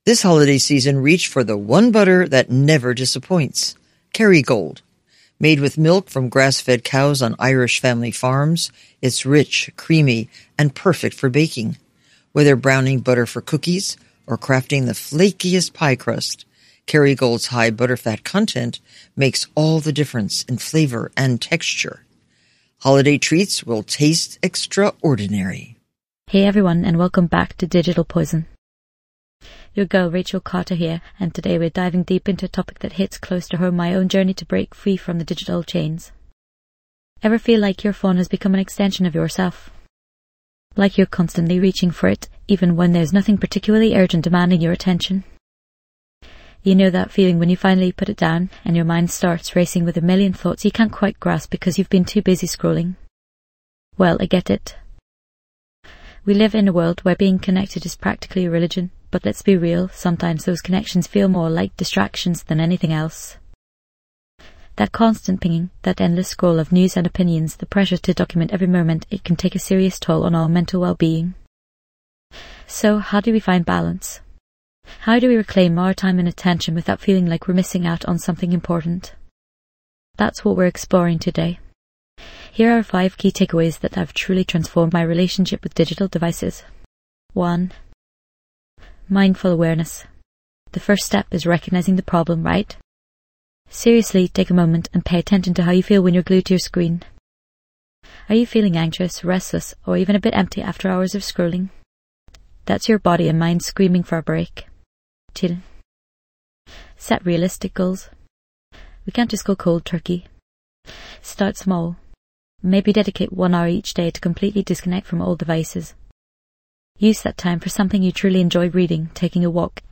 Listen as our guest shares their personal experiences, triumphs, and challenges in quitting digital devices for good, offering valuable insights on overcoming screen addiction and combating burnout.
This podcast is created with the help of advanced AI to deliver thoughtful affirmations and positive messages just for you.